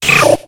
Cri de Héricendre dans Pokémon X et Y.